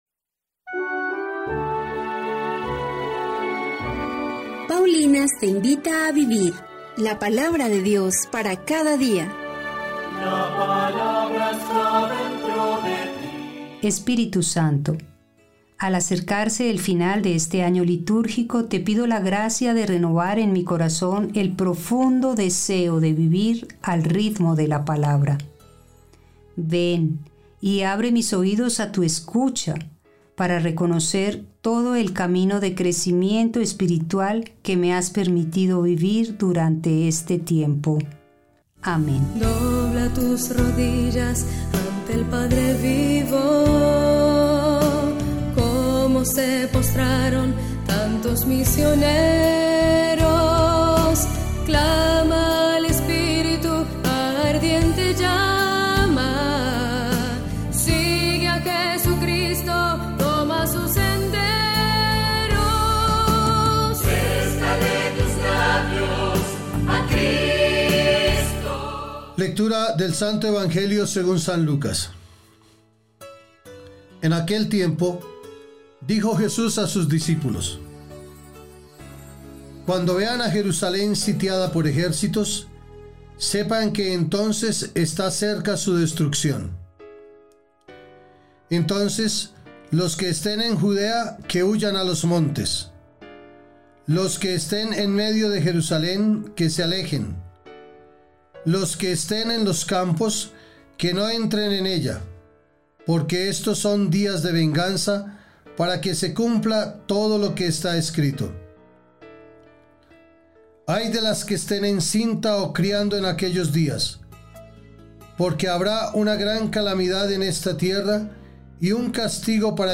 liturgia-28-de-Noviembre.mp3